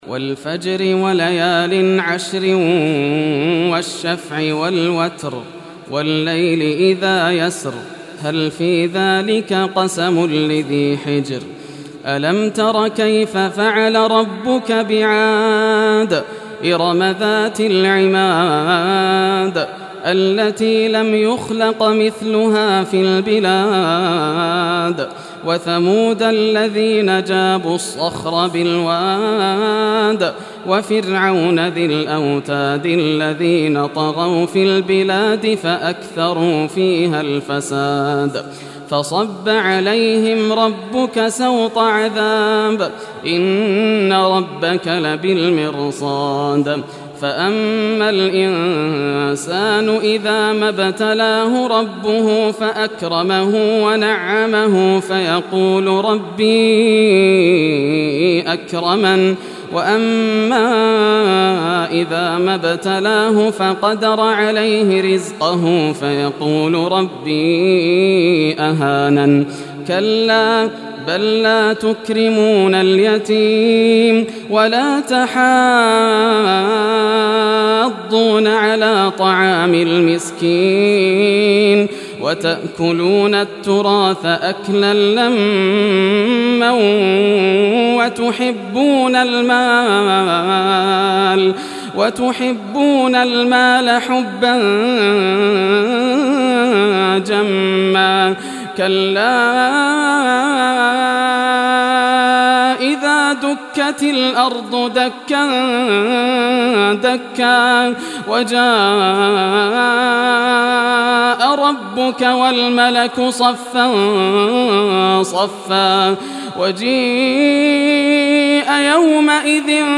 Surah Al-Fajr Recitation by Yasser al Dosari
Surah Al-Fajr, listen or play online mp3 tilawat / recitation in Arabic in the beautiful voice of Sheikh Yasser al Dosari.